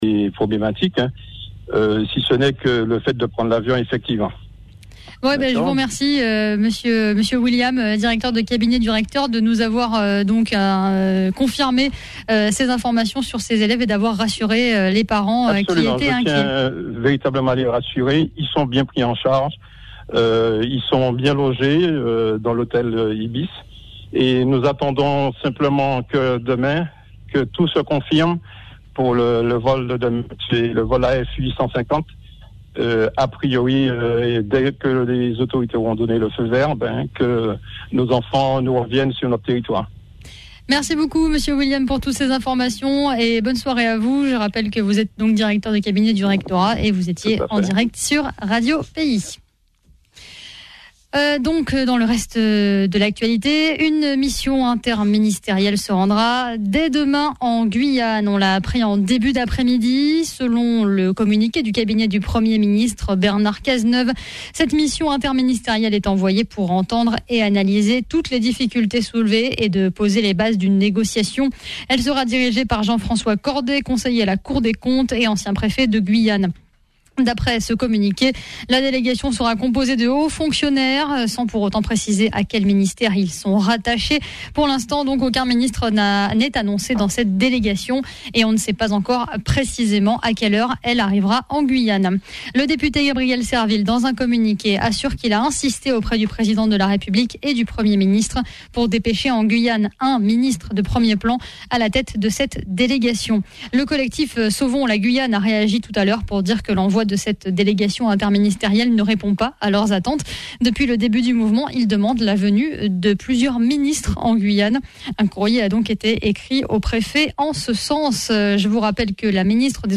Le dernier Flash info sur la situation en Guyane avec Radio pays.